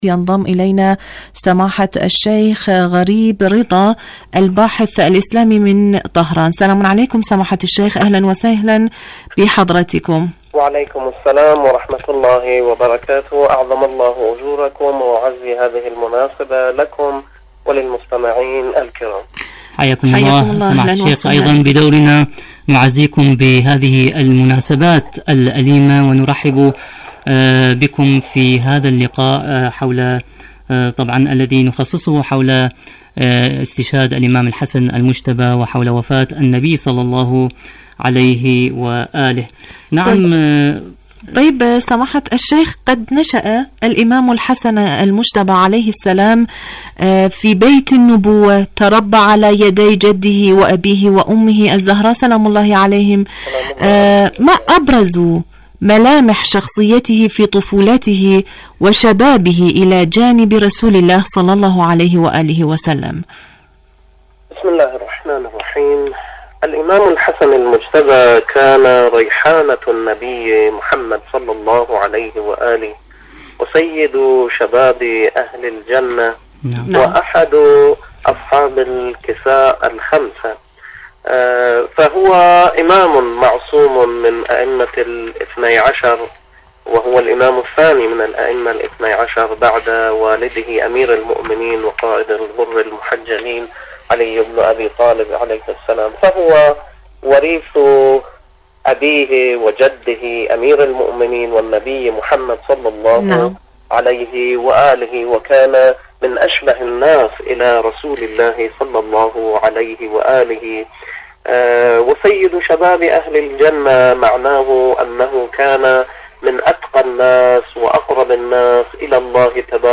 إذاعة طهران- رحيل النبي الأكرم (ص) وسبطه المجتبى (ع): مقابلة إذاعية